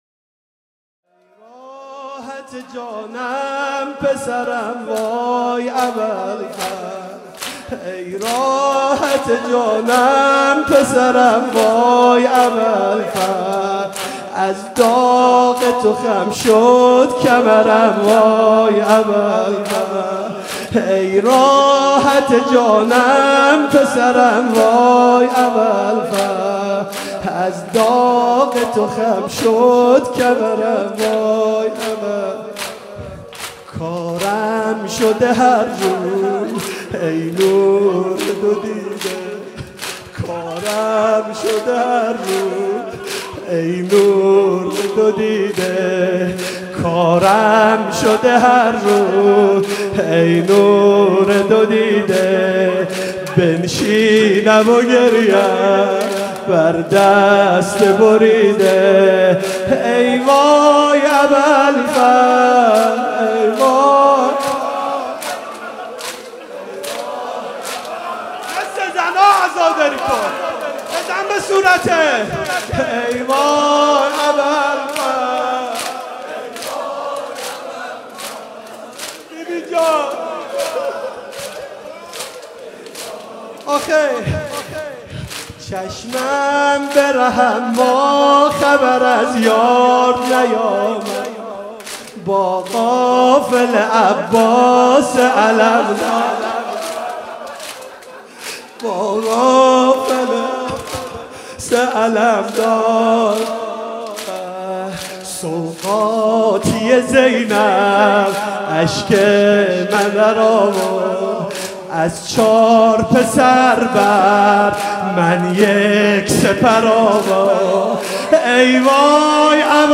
شب چهارم محرم 96 - هیئت شبان القاسم - ای وای ابالفضل